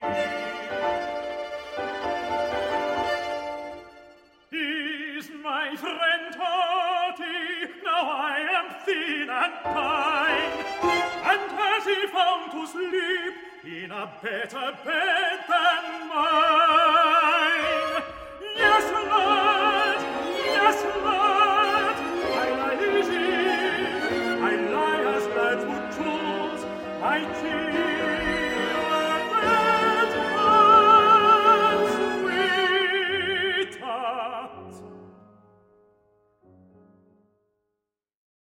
Orchestral
for tenor, piano and string quartet